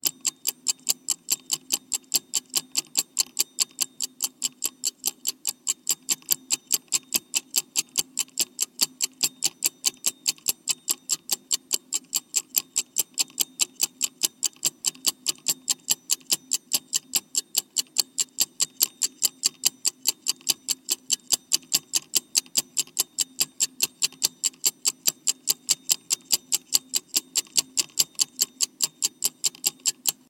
Catégorie: Alarmes